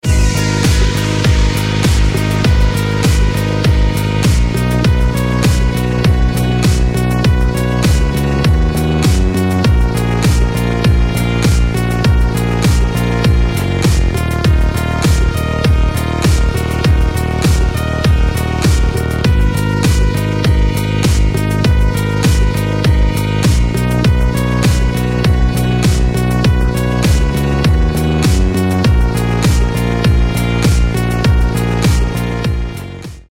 • Качество: 320, Stereo
крутые
спокойные
без слов
расслабляющие
electro house
Атмосферный и расслабляющий electro house